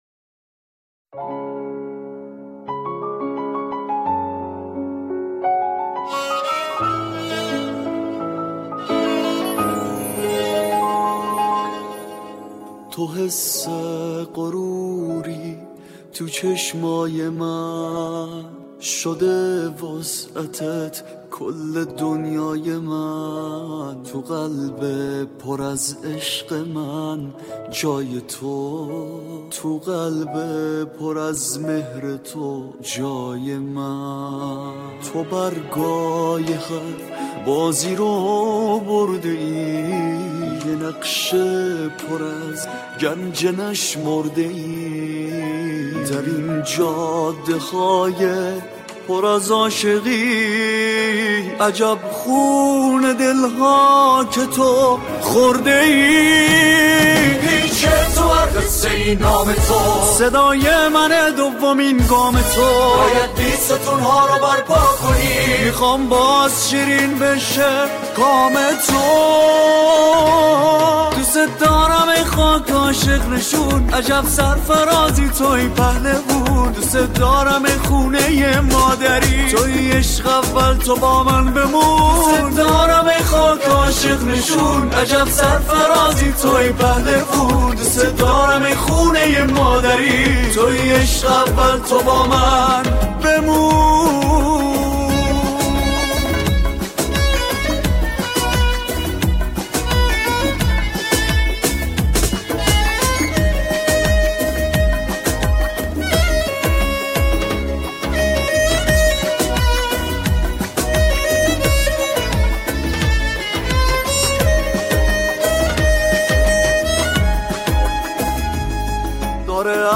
سرودهای مدافعان حرم